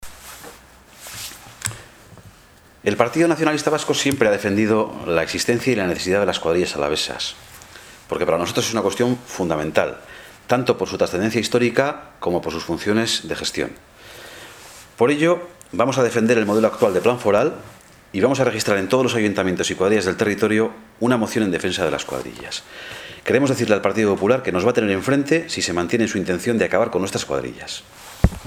Juntas Generales Araba: Ramiro Gonzalez en contra de la desaparición de las Cuadrillas